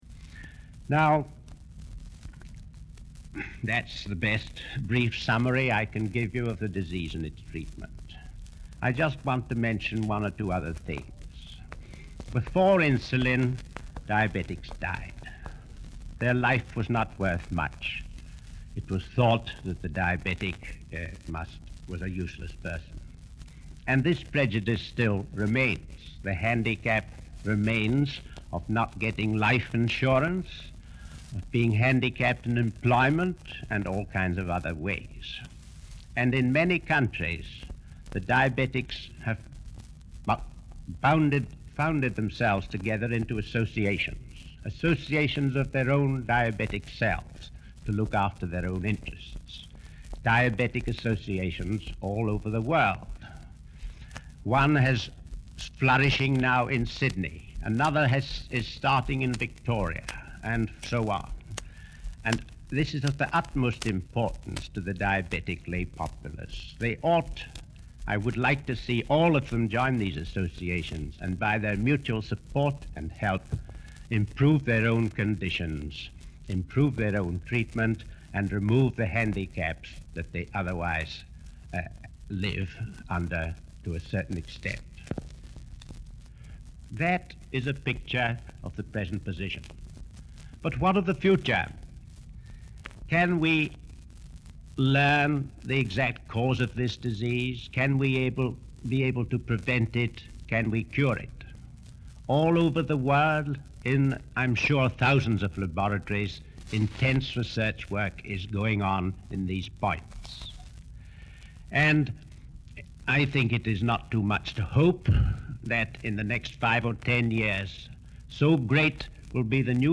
This is a recording of a speech made in 1953.